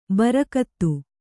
♪ barakattu